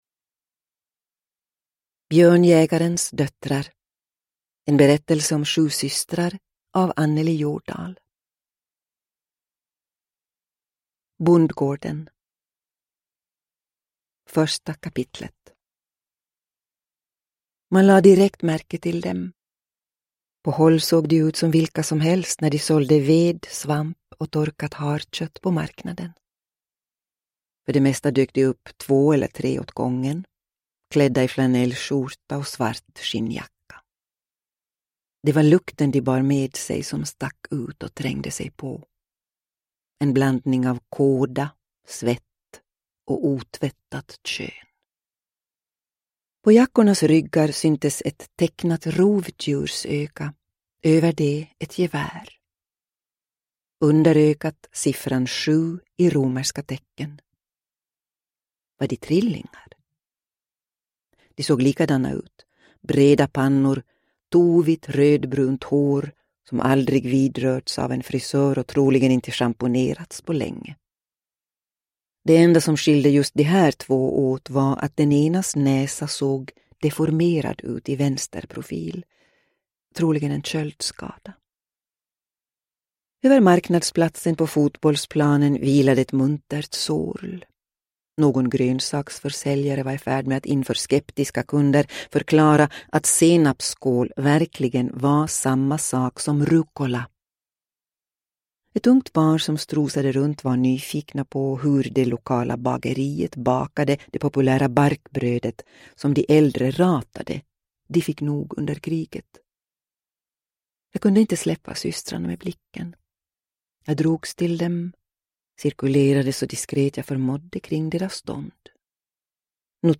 Uppläsare: Stina Ekblad